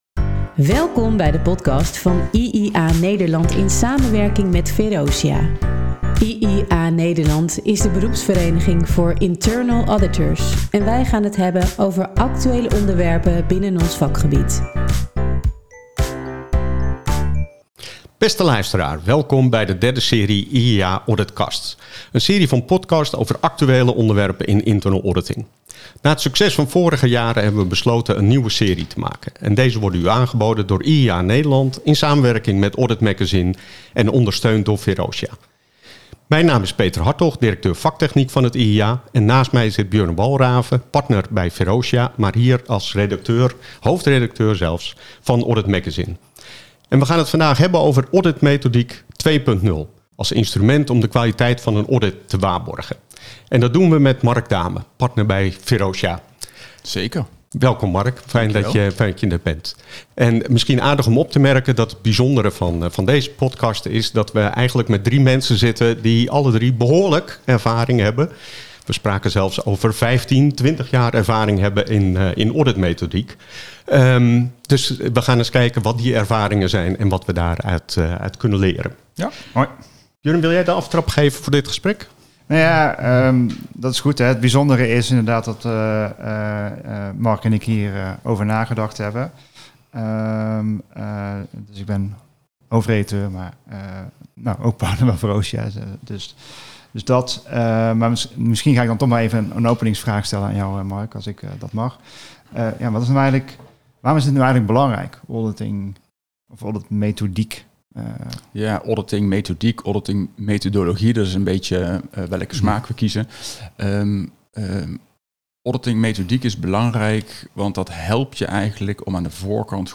Bijzonder aan deze podcast is de deelname van twee ervaren tafelgasten